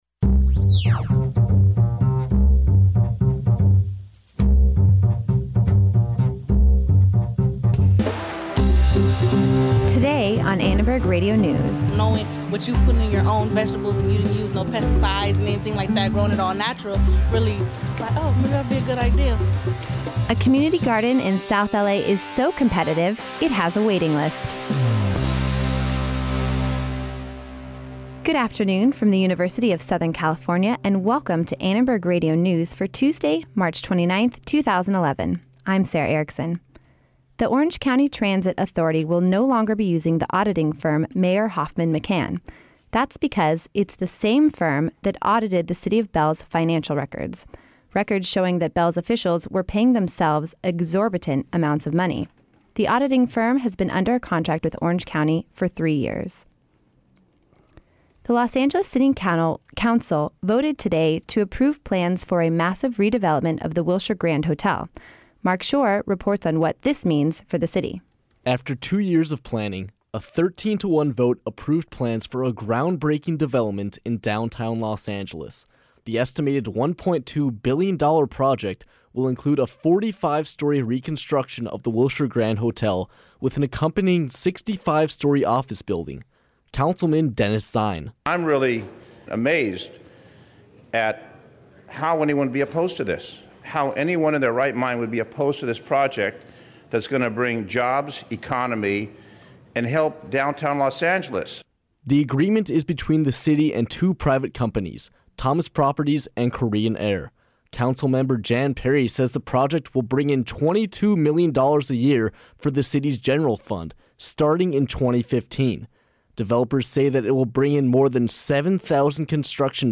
ARN Live Show - March 29, 2011 | USC Annenberg Radio News